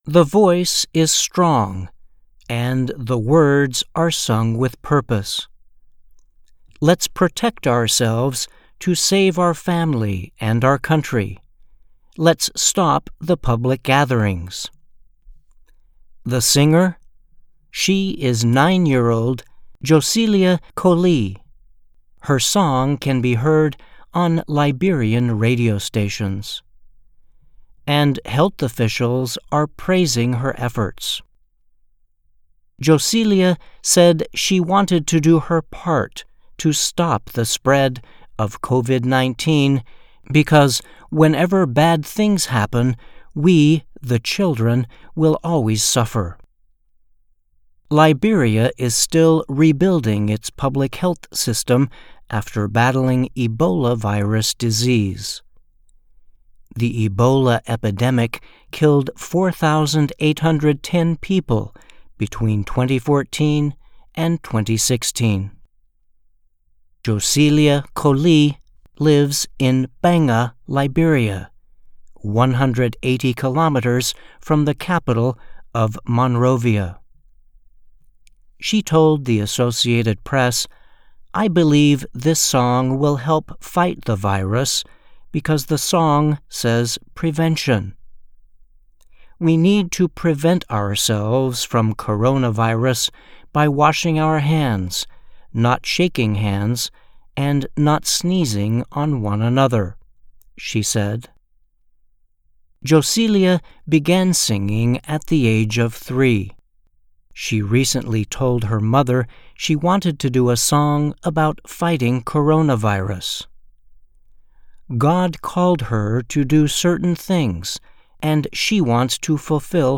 Embed share Liberian Radio Stations Play Girl’s Song About COVID-19 by VOA - Voice of America English News Embed share The code has been copied to your clipboard.